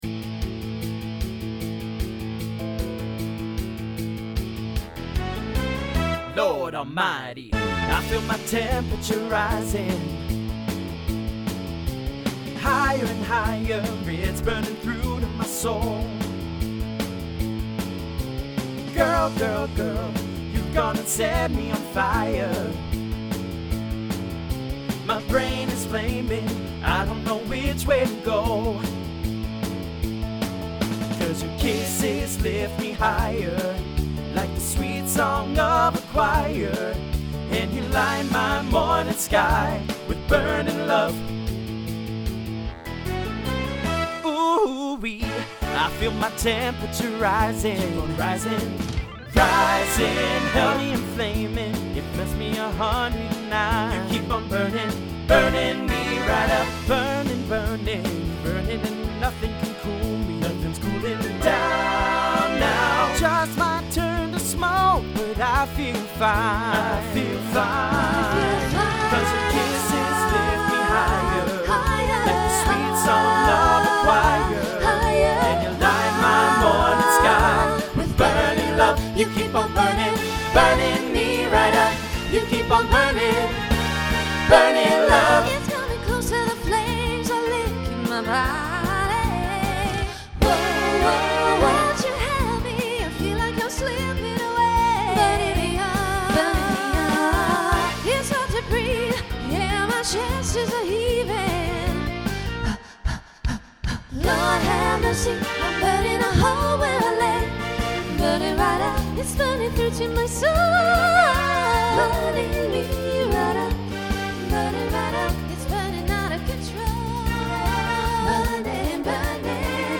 TTB/SSA
Voicing Mixed Instrumental combo
Broadway/Film , Rock